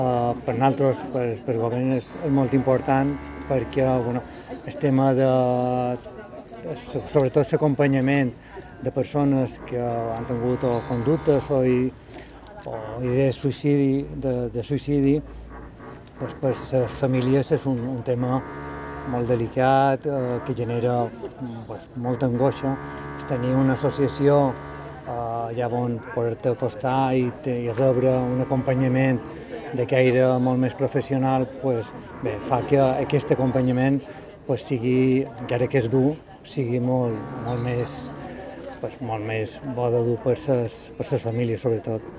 Declaraciones de Pep Falcó, director general de Asuntos Sociales